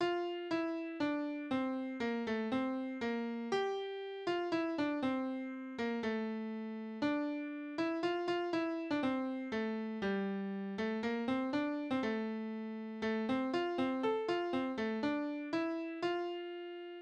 Dialoglieder:
Tonart: F-Dur
Tonumfang: große None
Besetzung: vokal